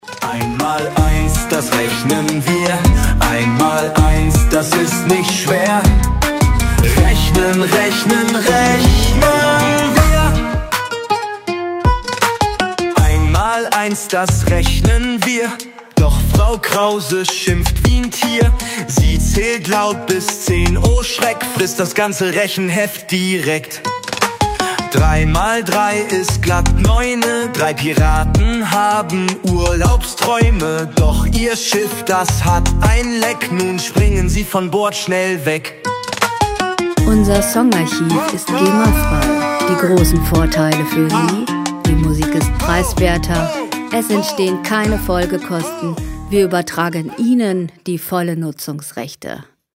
Gema-freie Kinderlieder
Musikstil: Deutschrap
Tempo: 80 bpm
Tonart: Es-Dur
Charakter: frech, keck